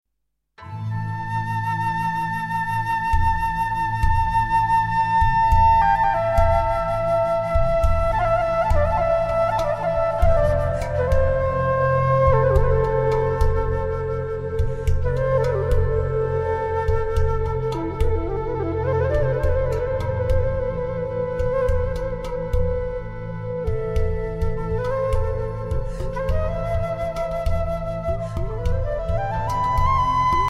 Ideal for relaxation and/or meditation